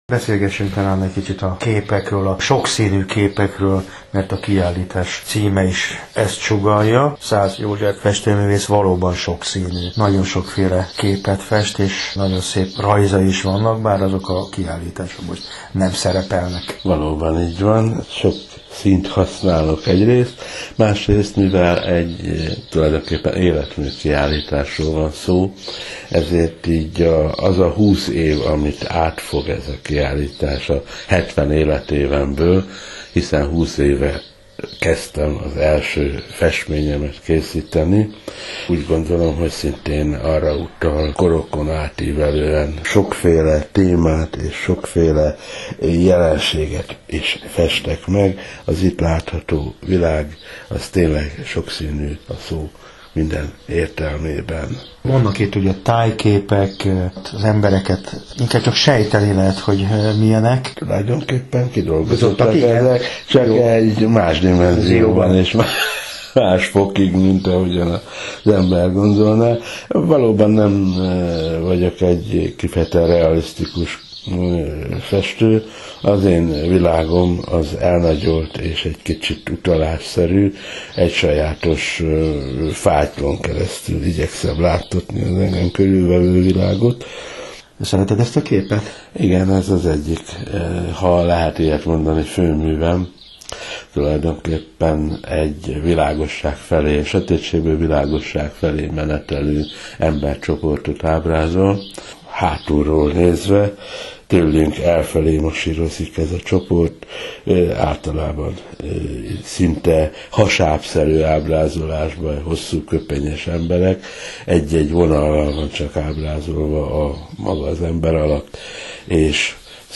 Jun, 2015 Amadeus Radio - Hungarian Interview regarding exhibition in Agora.